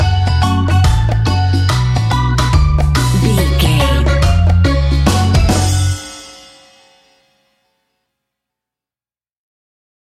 Aeolian/Minor
steelpan
drums
percussion
bass
brass
guitar